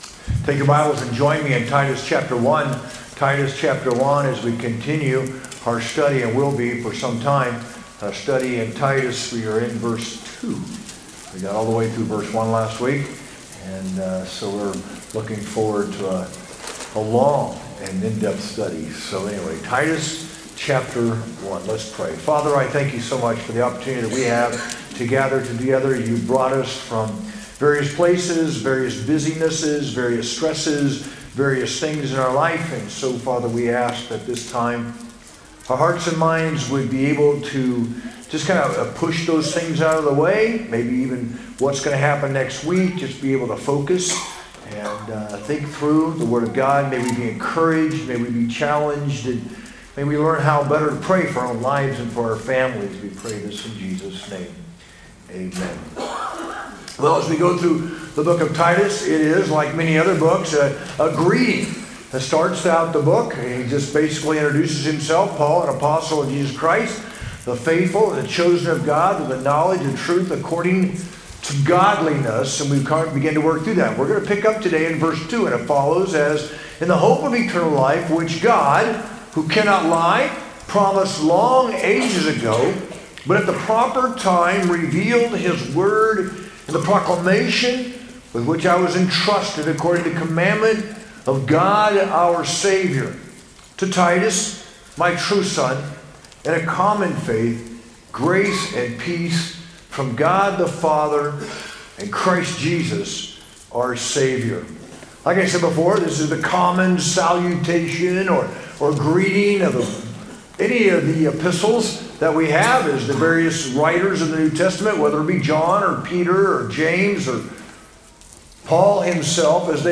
sermon-3-2-25.mp3